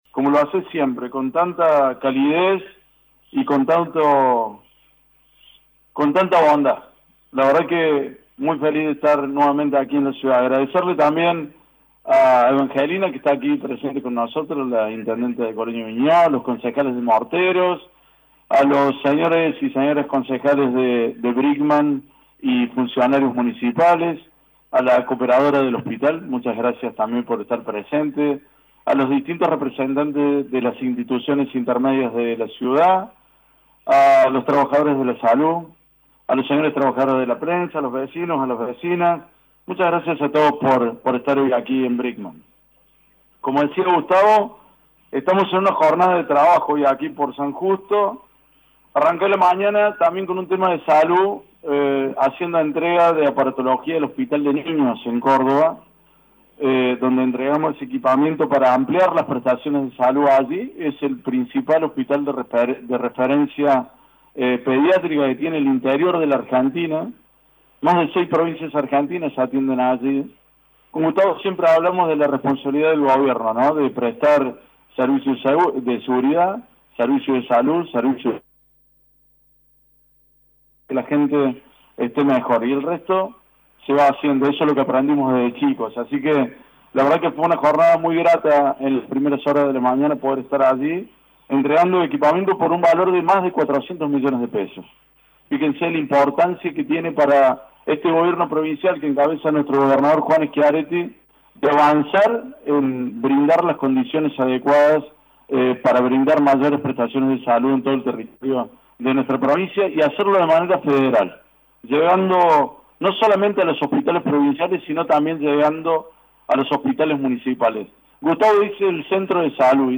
El acto se concretó en el andén de la renovada estación del ferrocarril.